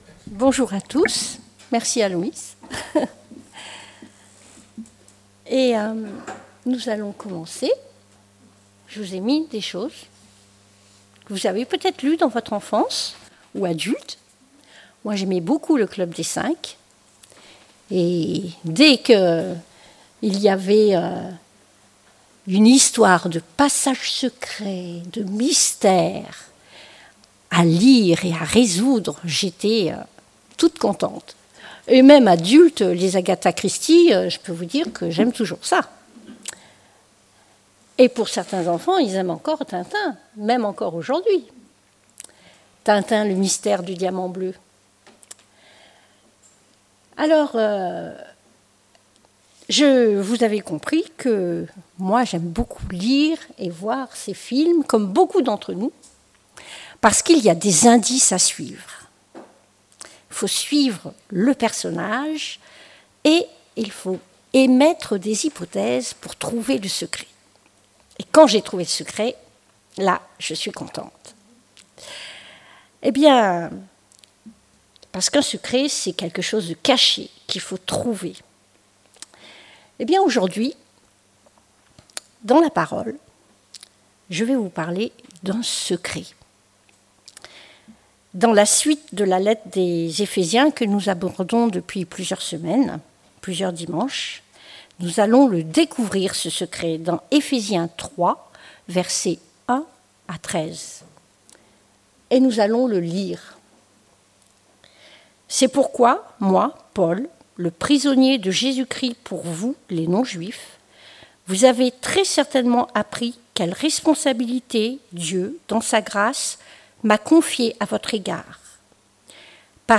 Ephésiens Prédication textuelle